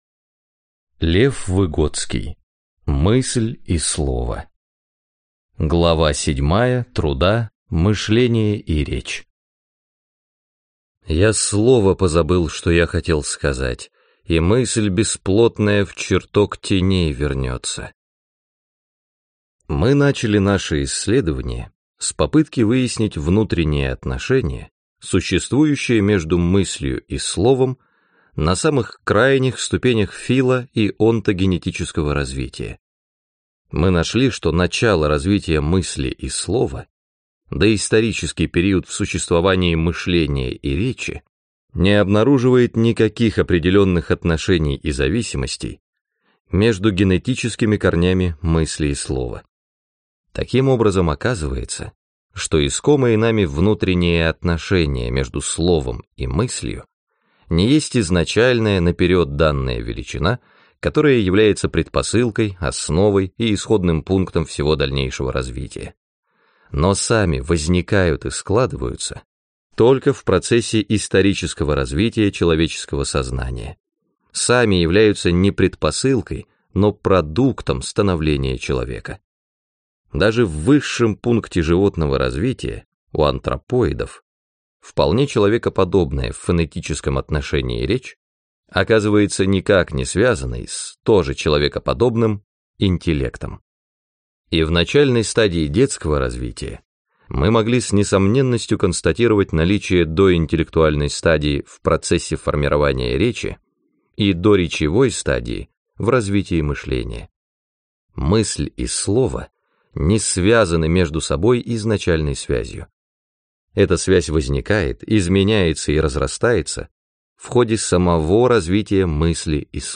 Аудиокнига Мысль и слово | Библиотека аудиокниг